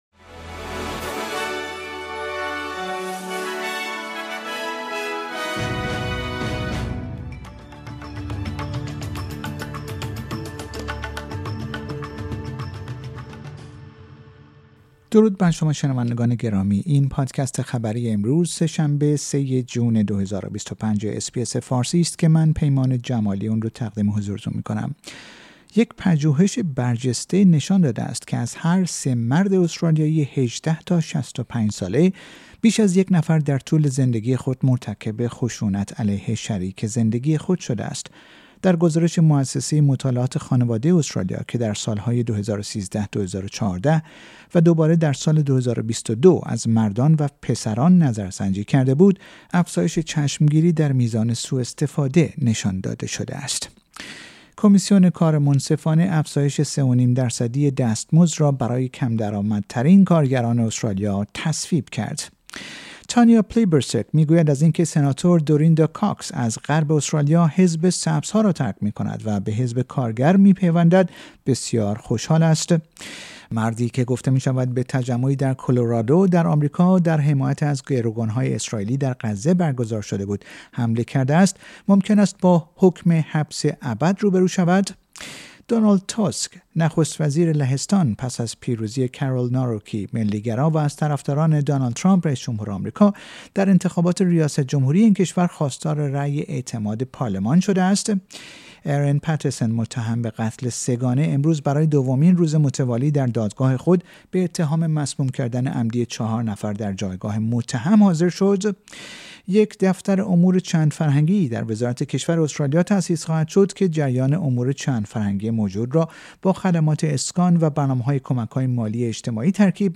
در این پادکست خبری مهمترین اخبار امروز سه شنبه ۳ مه ارائه شده است.